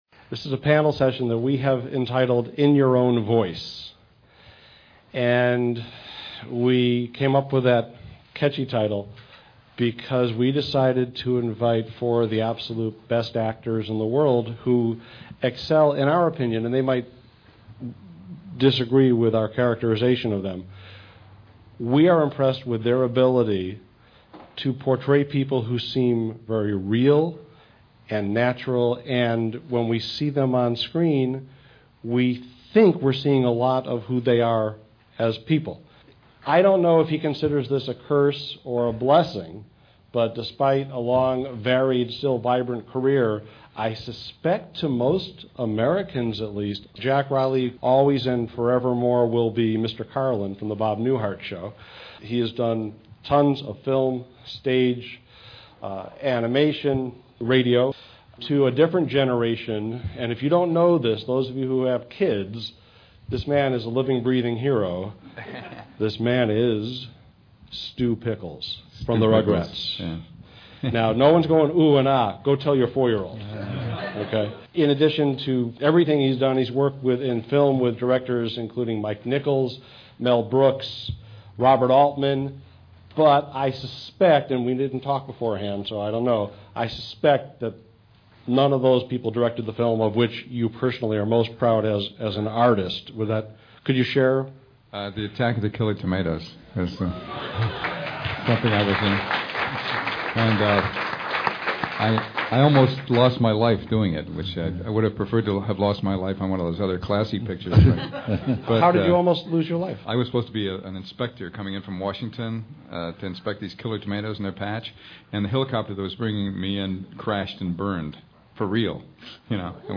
1-hour, 43-minute Audio Seminar; Instant Download!
(Wait until you hear their "cold reading" of commercial copy!)